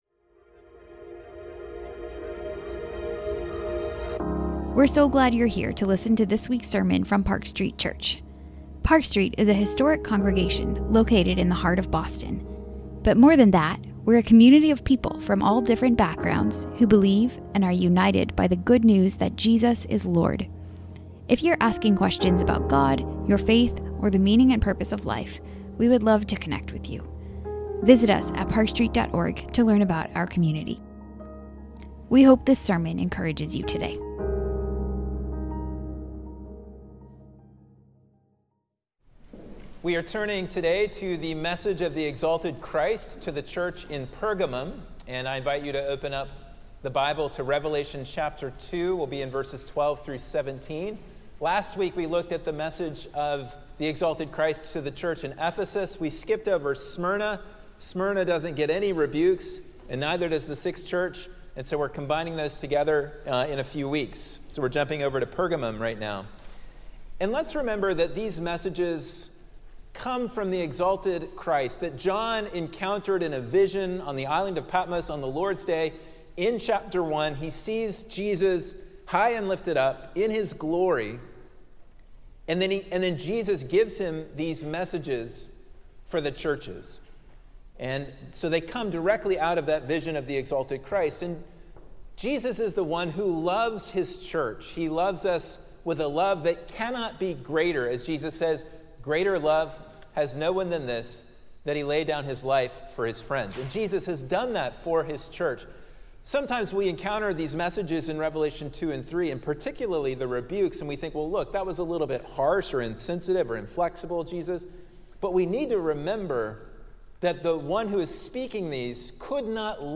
This sermon explores how Revelation calls us to urgent, faithful, worshipful, Christ-centered, and hope-filled witness amidst conflict as we wait for Christ to return.